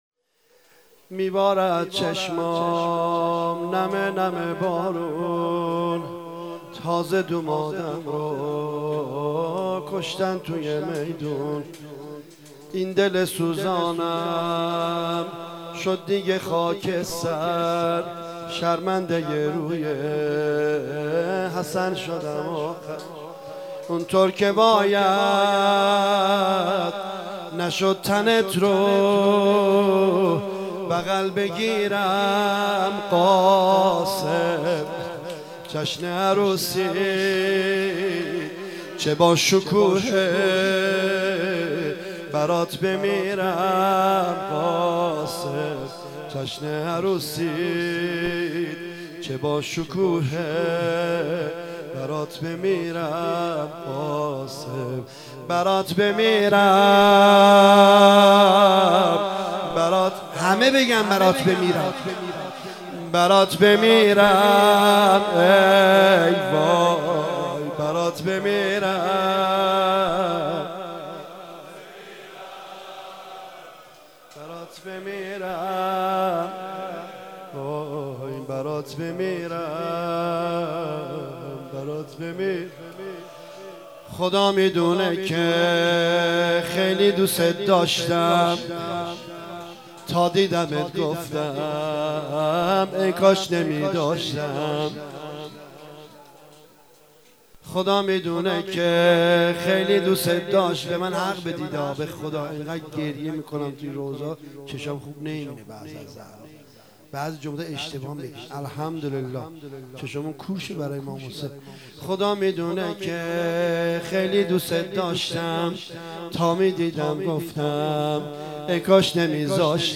مداحی
در شب ششم محرم الحرام 1396.